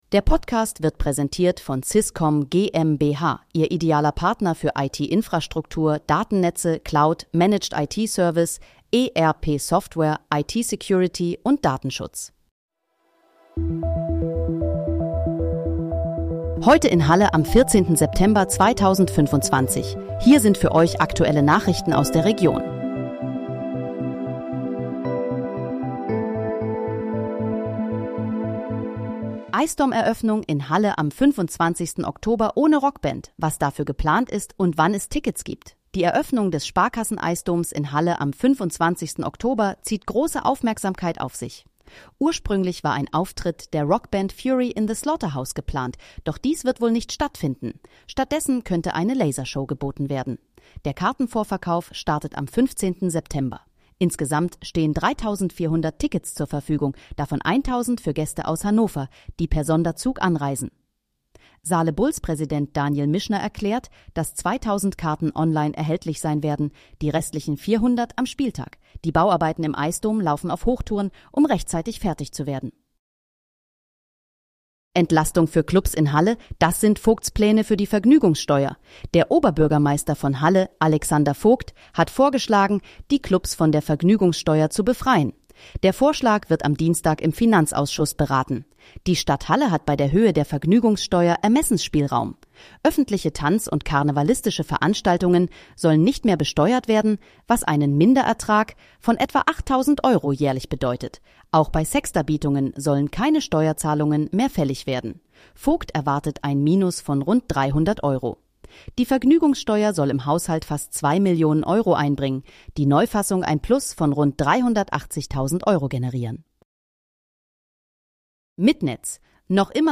Heute in, Halle: Aktuelle Nachrichten vom 14.09.2025, erstellt mit KI-Unterstützung
Nachrichten